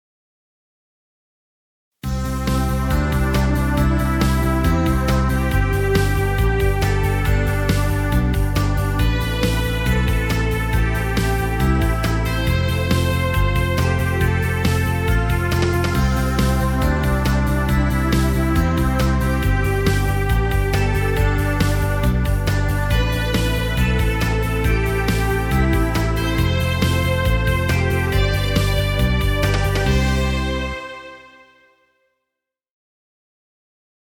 Background Music Royalty Free.